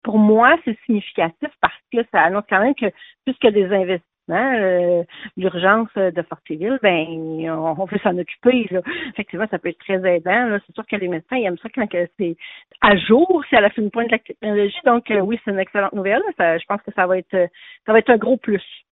Un avis que partage la mairesse de Fortierville, Julie Pressé.